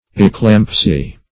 eclampsy - definition of eclampsy - synonyms, pronunciation, spelling from Free Dictionary Search Result for " eclampsy" : The Collaborative International Dictionary of English v.0.48: Eclampsy \Ec*lamp"sy\, n. (Med.)